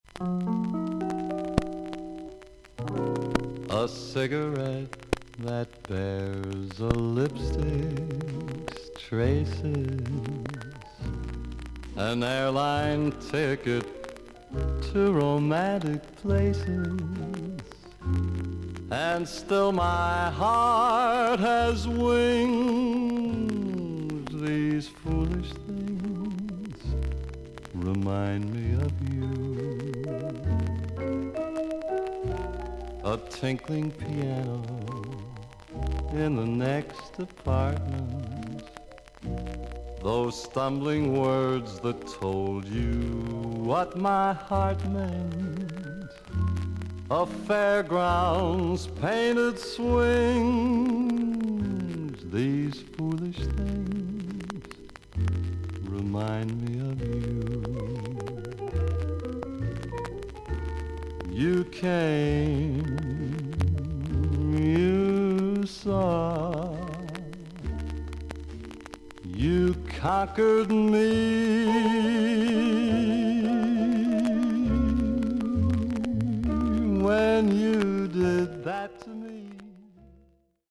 盤面全体に無数の長短のキズあり。全体に大きいサーフィス・ノイズとパチノイズあり。
ダンディーなヴェルヴェット・ヴォイスを持つ男性ジャズ・シンガー。